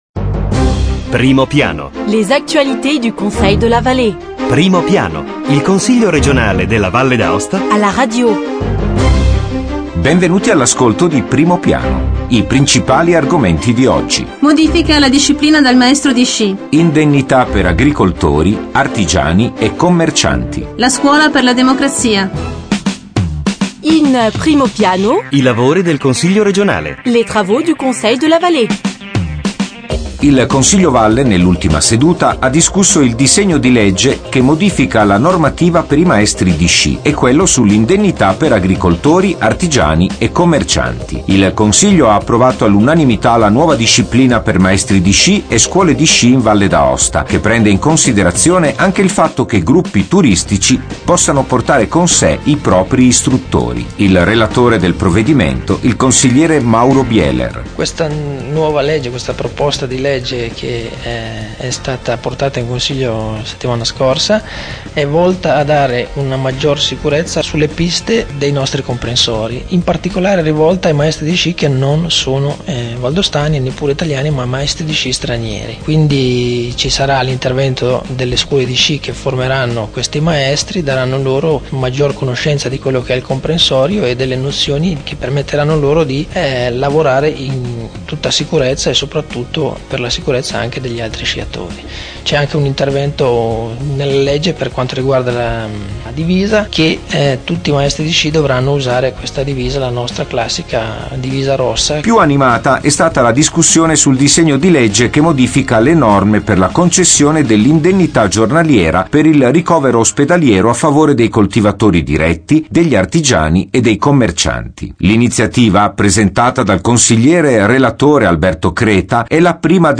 Evénements et anniversaires Documents liés De 20 octobre 2009 à 27 octobre 2009 Primo piano Le Conseil r�gional � la radio: approfondissement hebdomadaire sur l'activit� politique, institutionnelle et culturelle de l'assembl�e l�gislative. Voici les th�mes de la nouvelle transmission: - S�ance du Conseil de la Vall�e: nouvelle loi pour les moniteurs de ski, avec l'interview au Conseiller Mauro Bieler; - Approbation du projet de loi portant indemnit�es aux agriculteurs, artisans et d�taillants: interview au Conseiller Massimo Lattanzi. - Travaux des commissions du Conseil; - Scuola per la democrazia: interview au Pr�sident du Conseil de la Vall�e, Alberto Cerise.